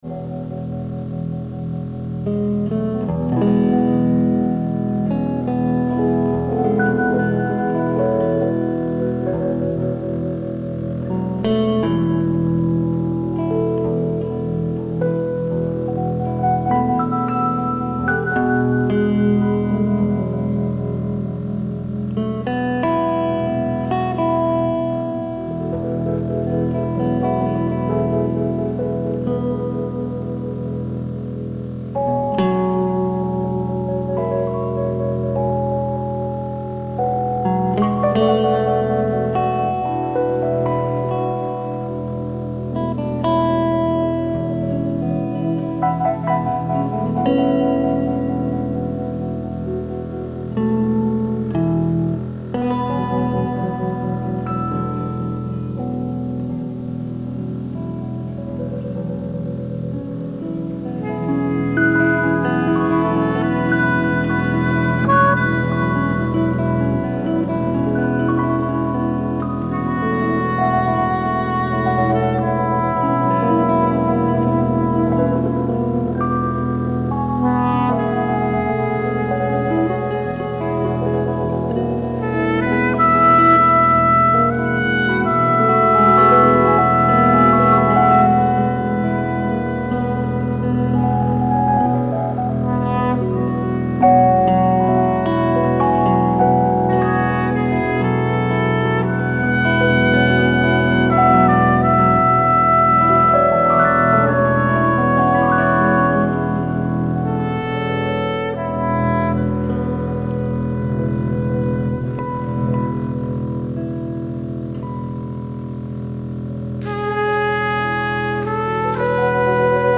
guitariste
un saxophone soprano